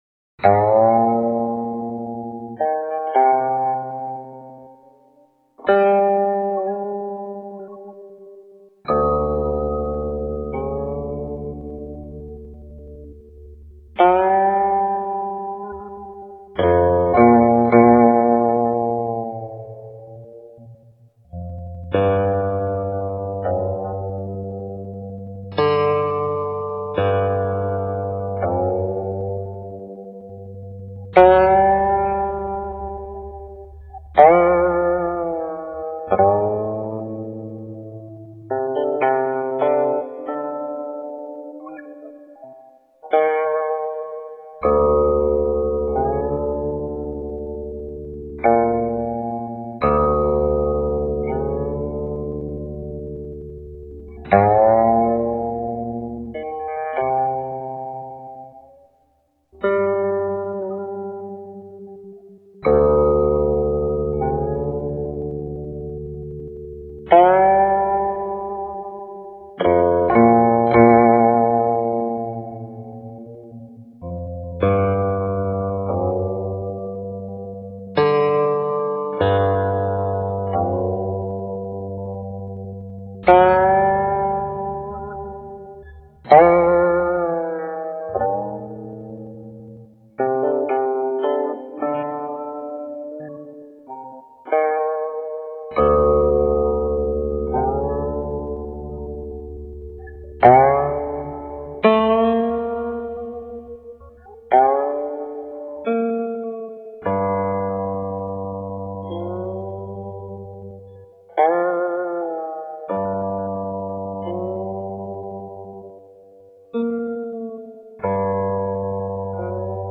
0198-古琴曲秋月照茅亭.mp3